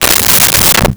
Dresser Drawer Close 01
Dresser Drawer Close 01.wav